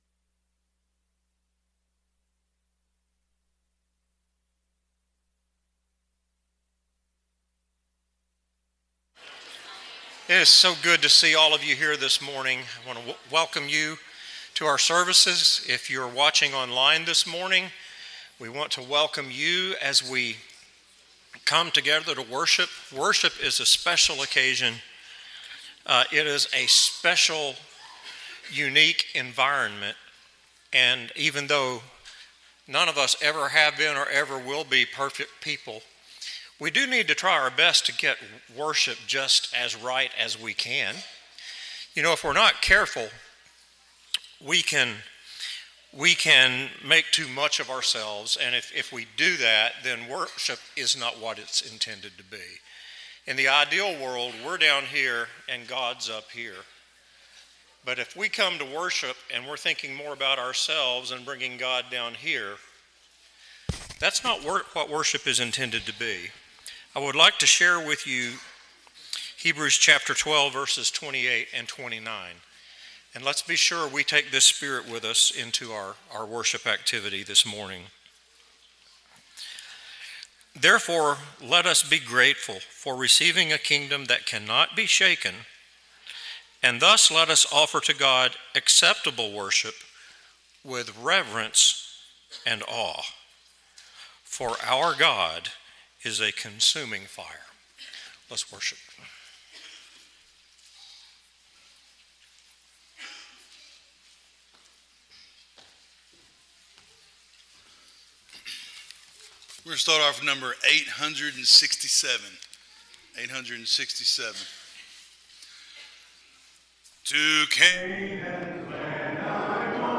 Luke 19:9, English Standard Version Series: Sunday AM Service